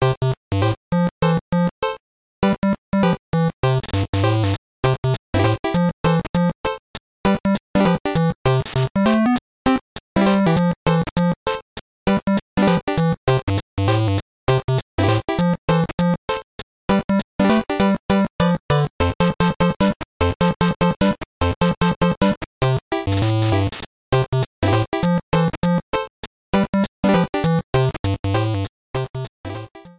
Main Menu (and calendar) theme